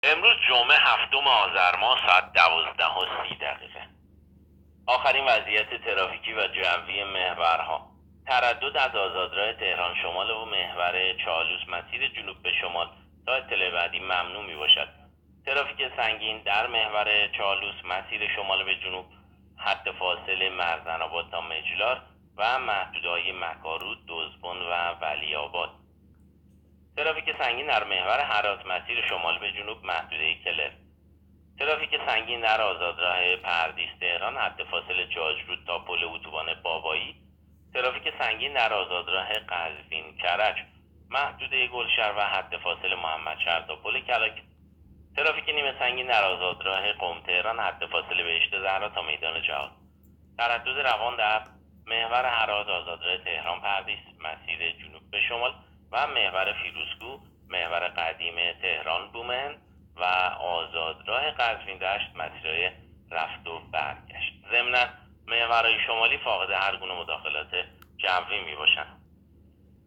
گزارش رادیو اینترنتی از آخرین وضعیت ترافیکی جاده‌ها ساعت ۱۳ هفتم آذر؛